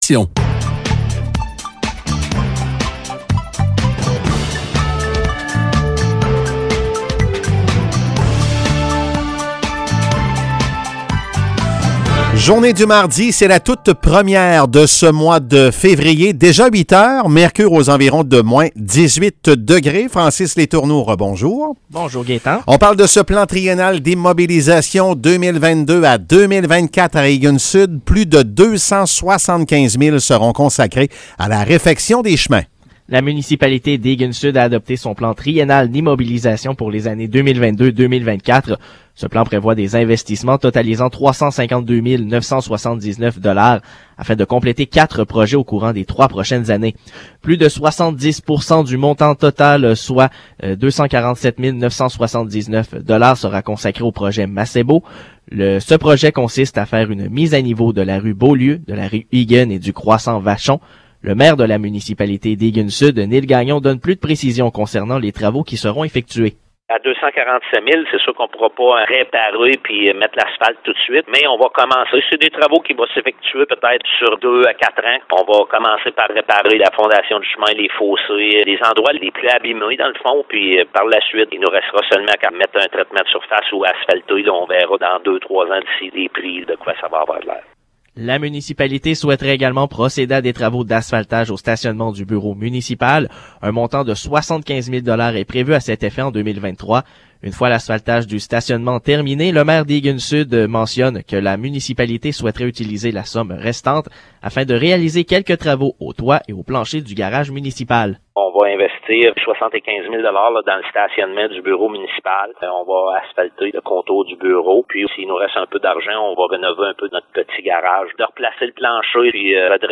Nouvelles locales - 1er février 2022 - 8 h